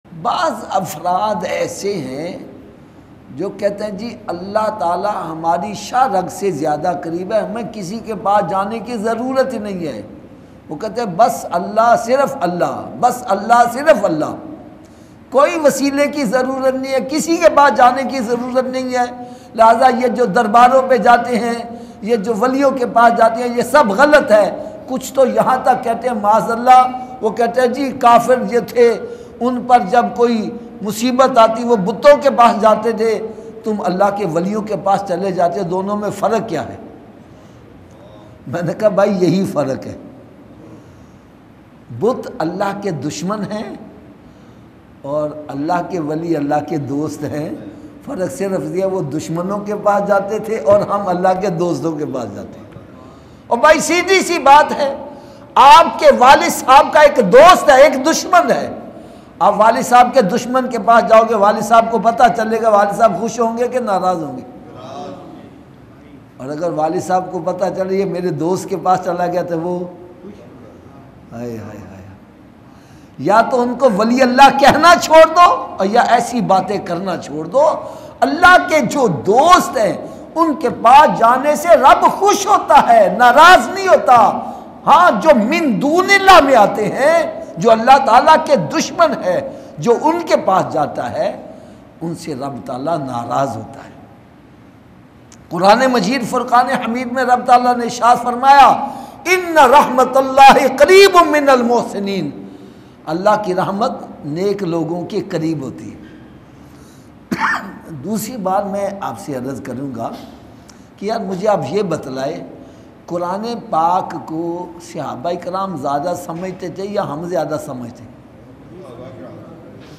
Allah ka Dost World's Best Bayan Download MP3